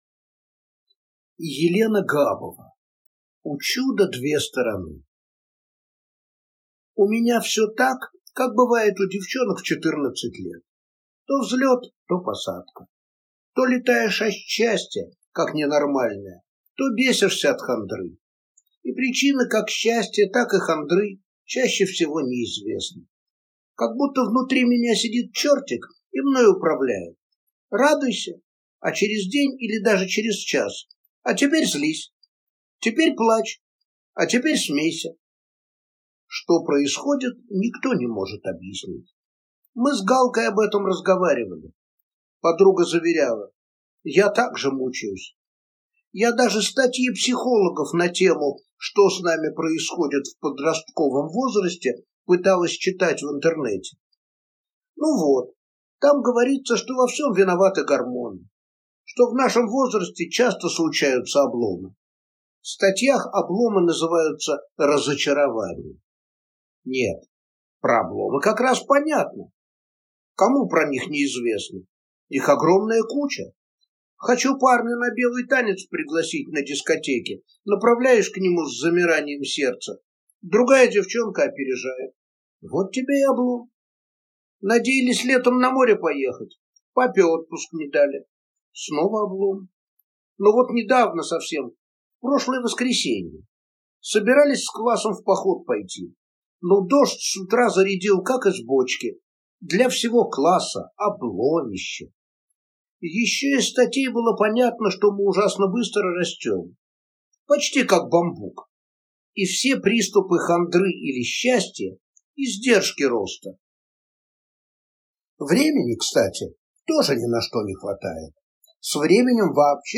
Аудиокнига У чуда две стороны | Библиотека аудиокниг